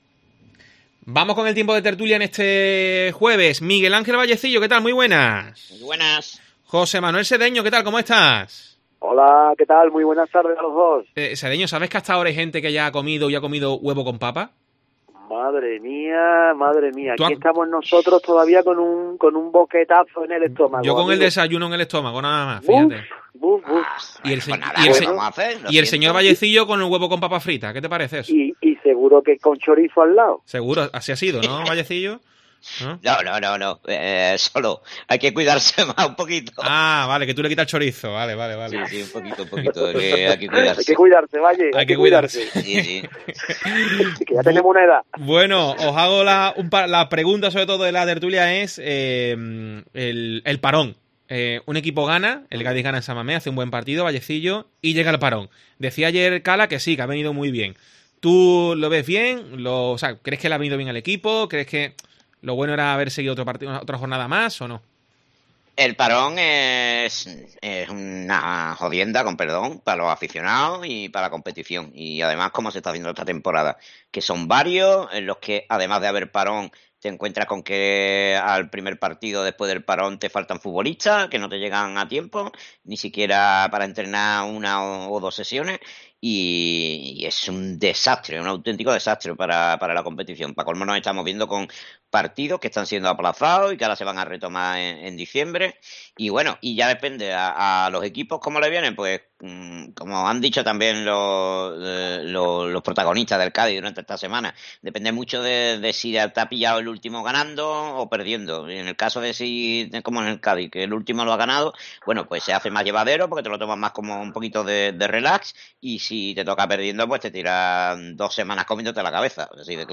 El debate de Deportes COPE sobre el Cádiz CF